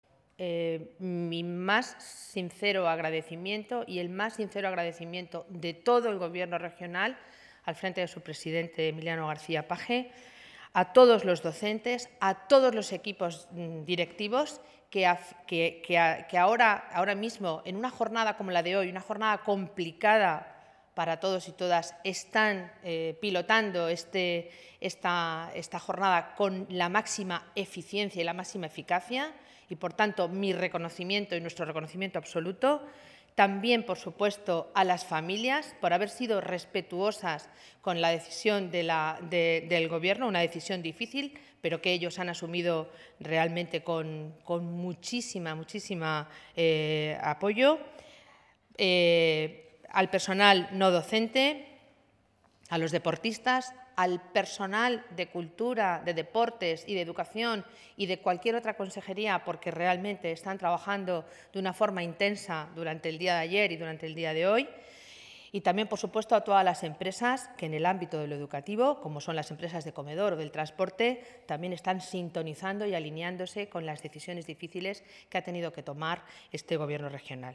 >> La consejera de Educación, Cultura y Deportes se ha pronunciado de este modo en rueda de prensa celebrada en el Palacio de Fuensalida (Toledo)